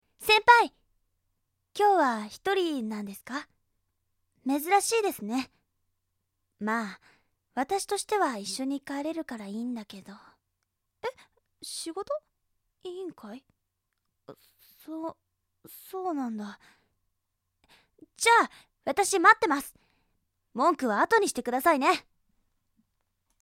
girl2.mp3